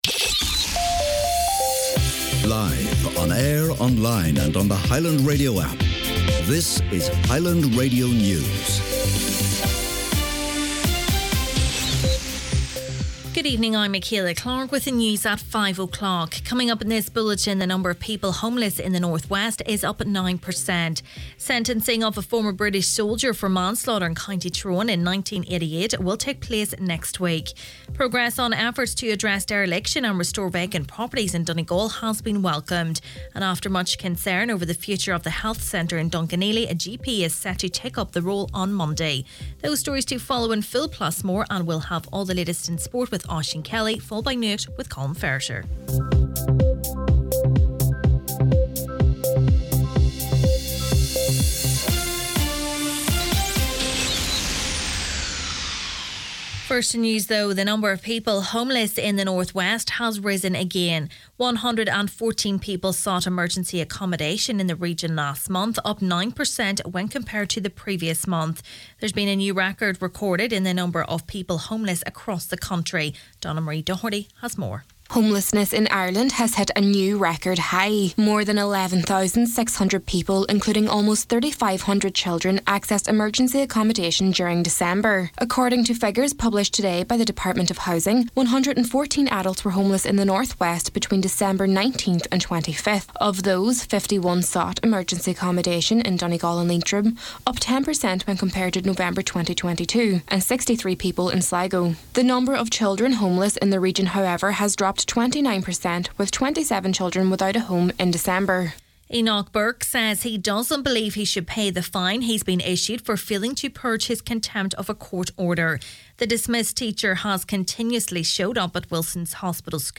Main Evening News, Sport, Nuacht and Obituaries – Friday January 27th